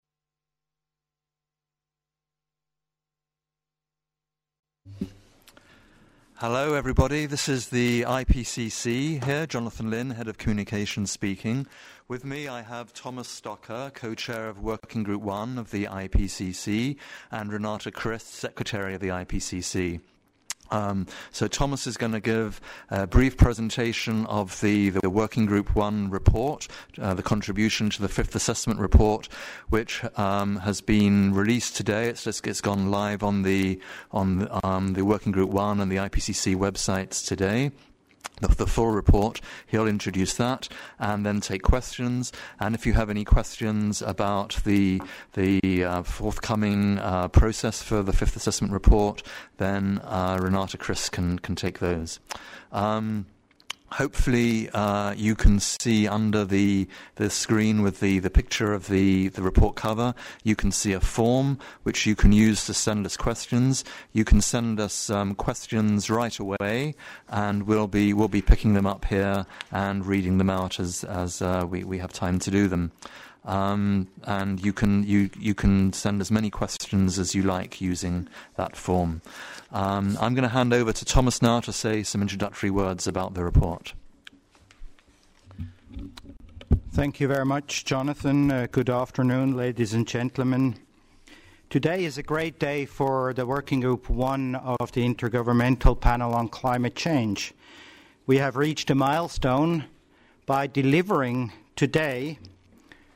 AR5_wg1_press_conference.mp3